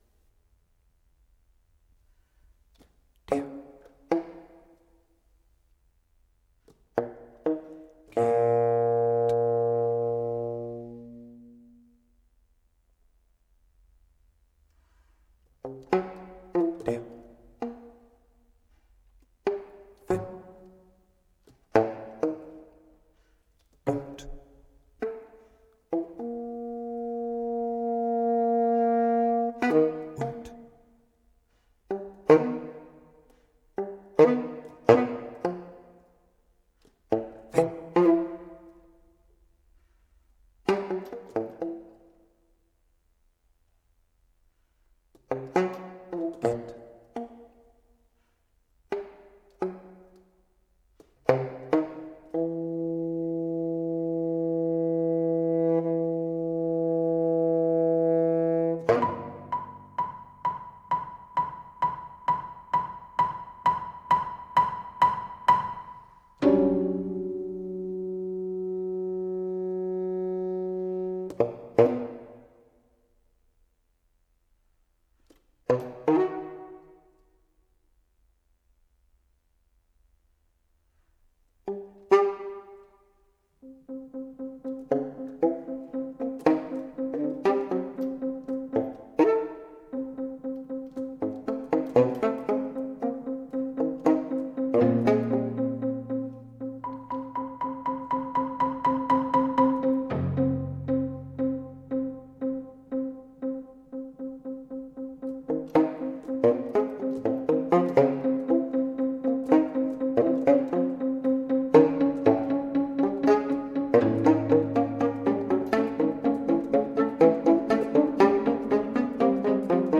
soprano and tenor saxophone
piano and voice